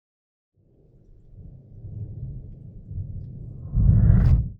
FIREBLOCKALARM.wav